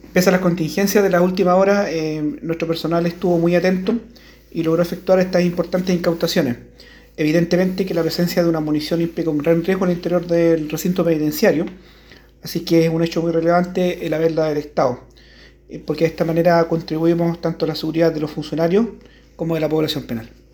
El Director Regional de Gendarmería en Los Lagos, Coronel Edgardo Caniulef Gajardo, destacó la importancia de estas incautaciones, señalando que pese a las contingencias de las últimas horas, el personal penitenciario logró efectuar estas importantes incautaciones.